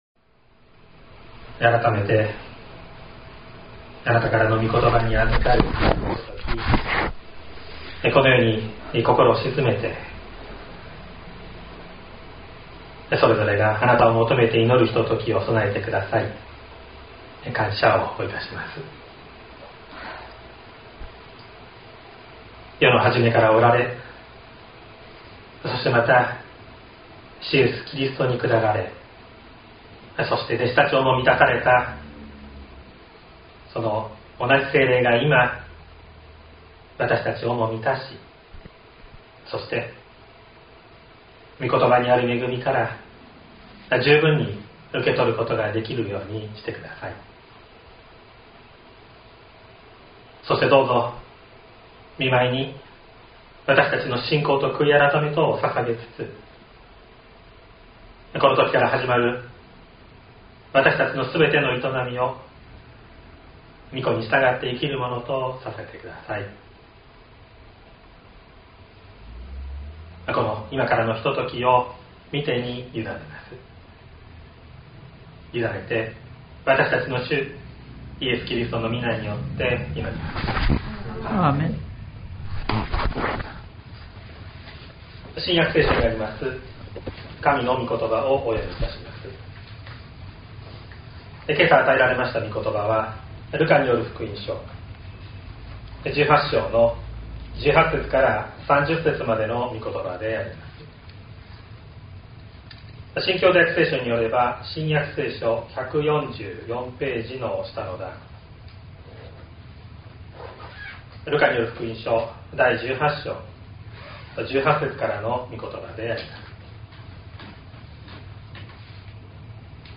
2025年06月08日朝の礼拝「わたしに従いなさい」西谷教会
説教アーカイブ。
音声ファイル 礼拝説教を録音した音声ファイルを公開しています。